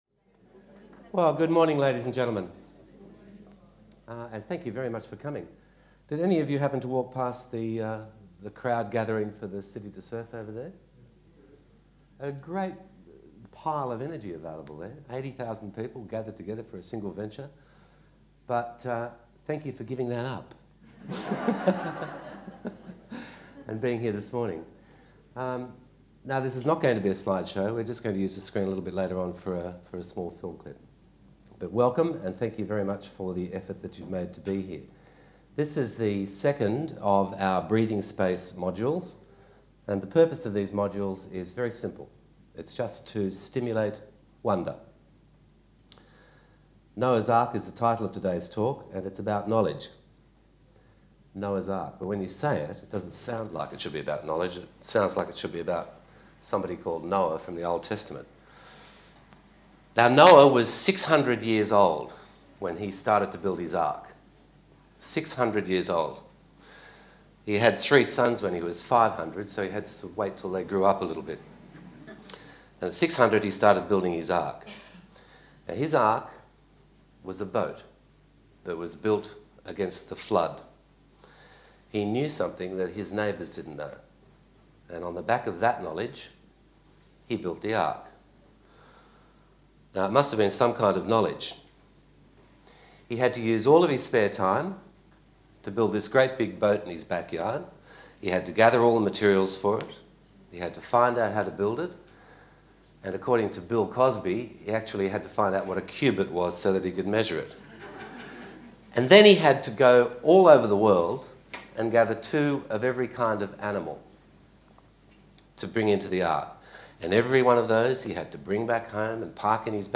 Breathing Space A series of talks on subjects that matter